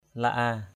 /la-a:/ (cv.) li-a l{a% 1. (d.) cây là a (họ tre) = espèce de bamboo. 2. (d.) làng Là A = village de La-a. 3.